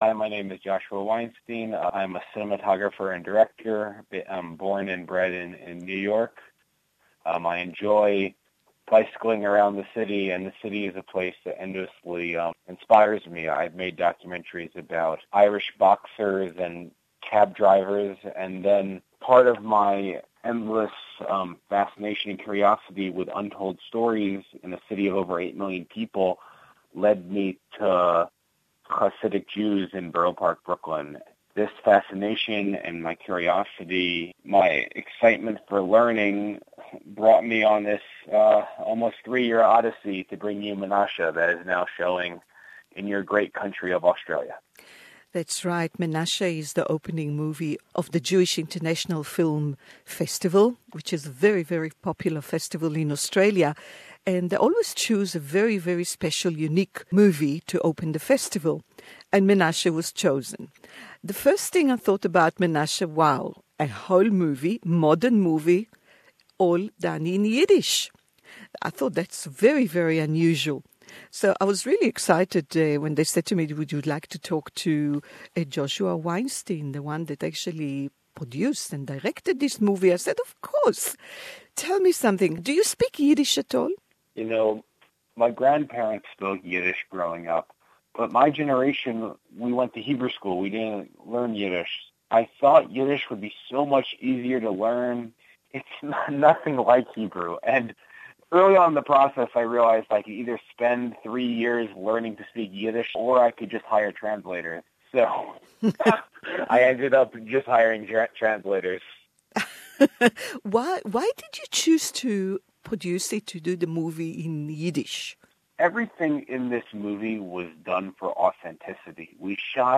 A fascinating interview